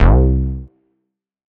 Sub Bass - Ritter - C Slide Up.wav